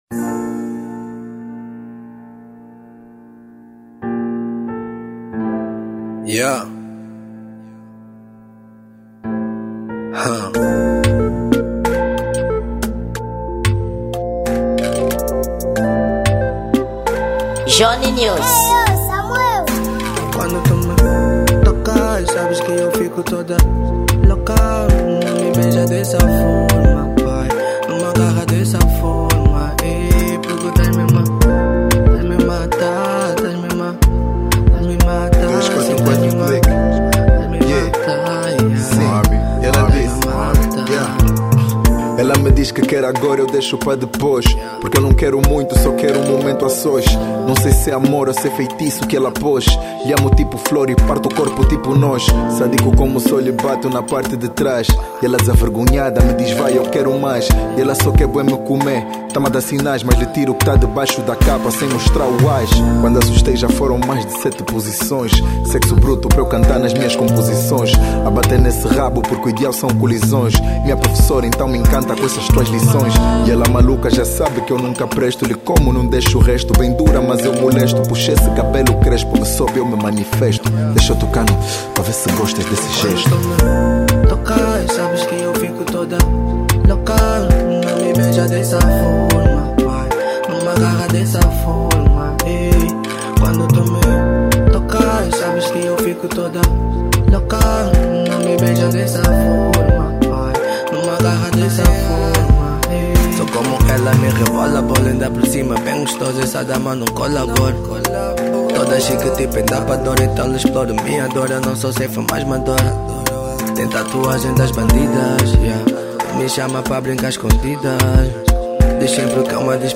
Rap
Gênero: Zouk